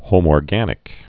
(hōmôr-gănĭk)